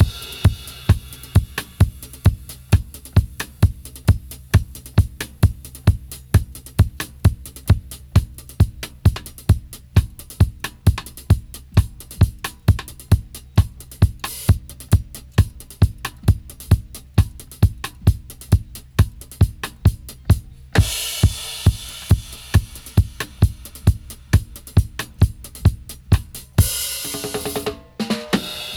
134-DRY-04.wav